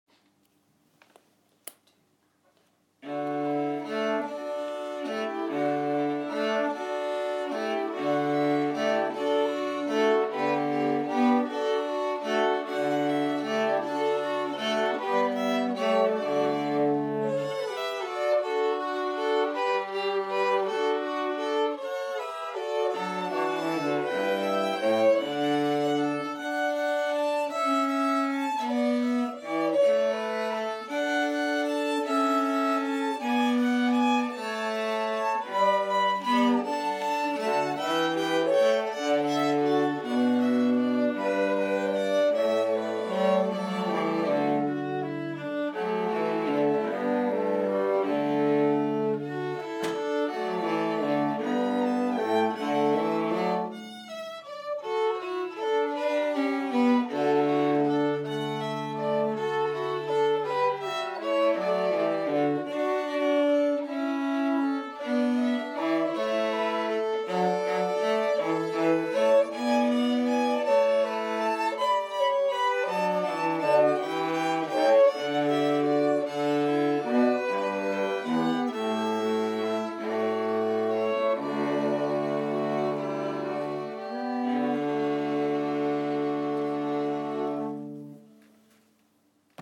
quartet v1